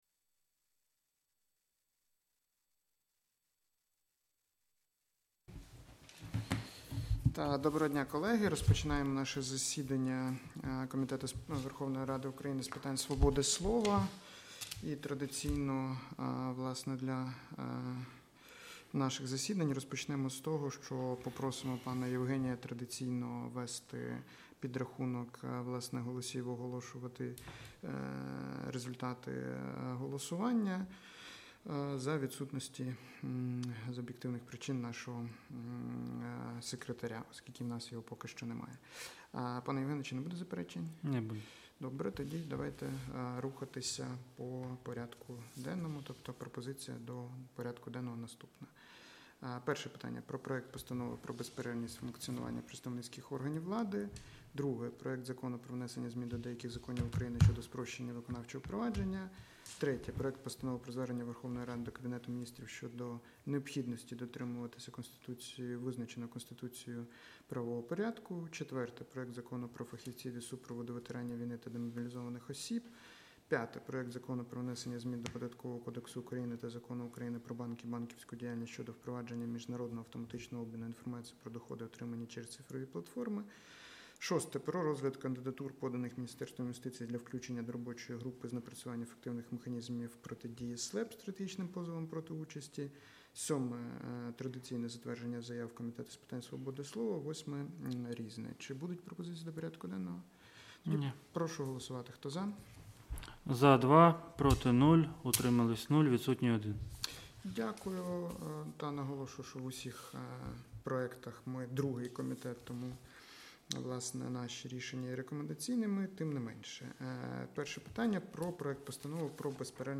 Аудіозапис засідання Комітету від 16 вересня 2025р.